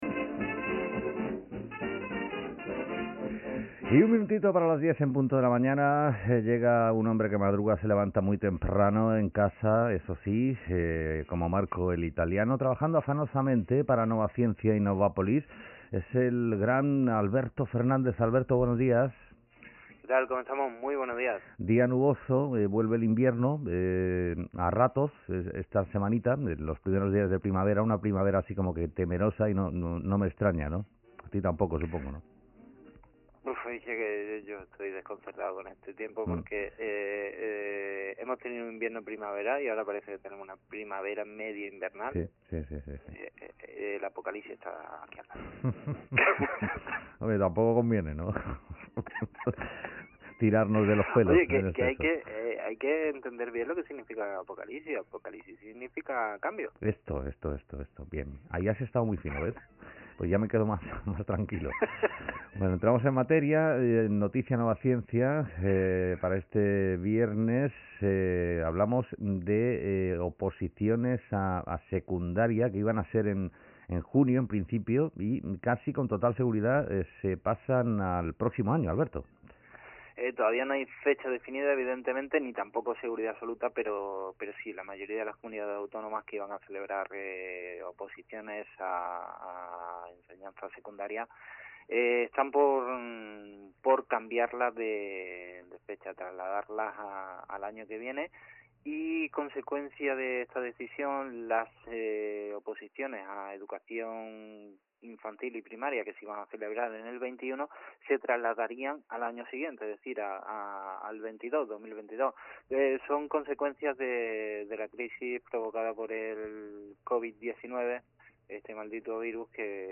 desde casa